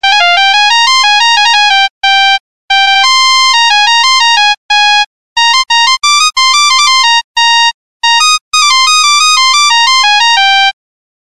NokiaSamsung рингтоны. Арабские
(народная)